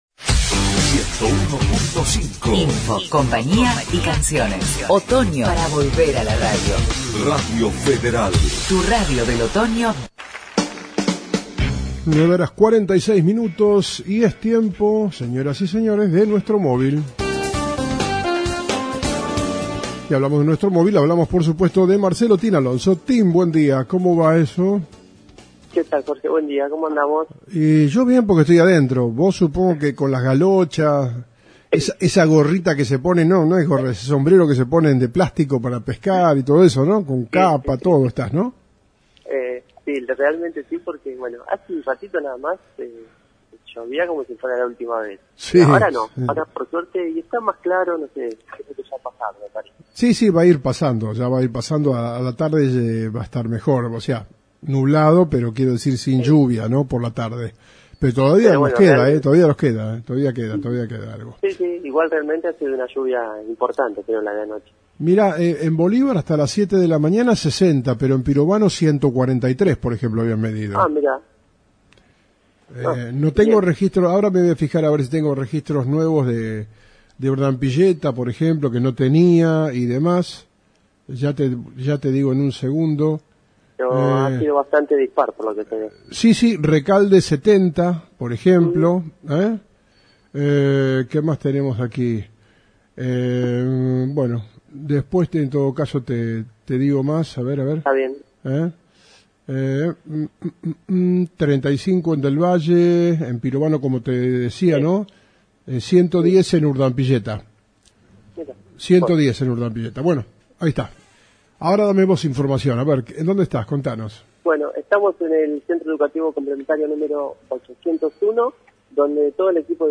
Entrevista a las autoridades